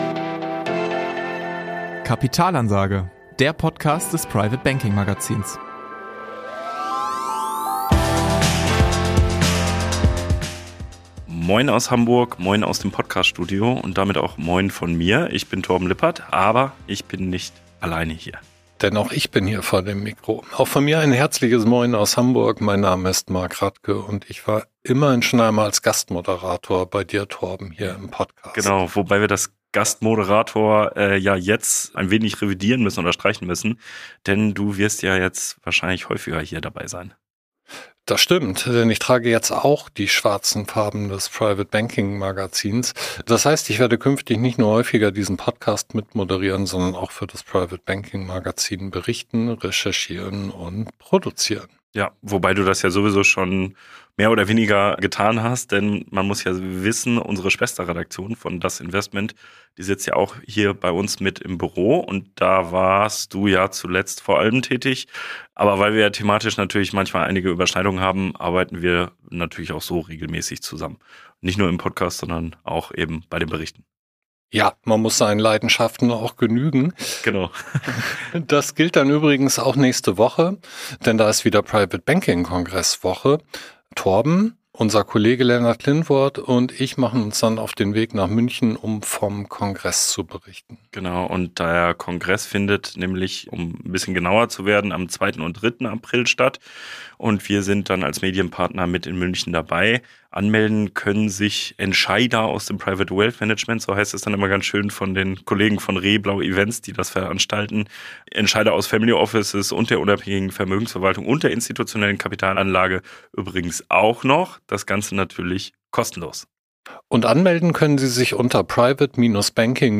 Außerdem im Interview